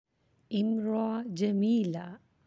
(imra’a jameela)